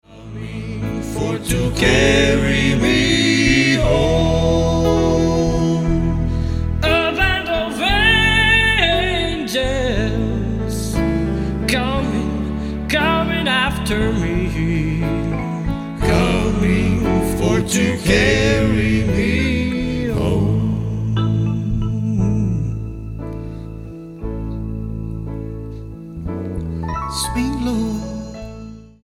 STYLE: World